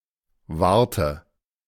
The river Warta (/ˈvɑːrtə/ VAR-tə, Polish: [ˈvarta] ; German: Warthe [ˈvaʁtə]
De-Warthe.ogg.mp3